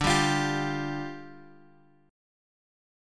Dmbb5 chord {x 5 5 x 6 3} chord
D-Minor Double Flat 5th-D-x,5,5,x,6,3.m4a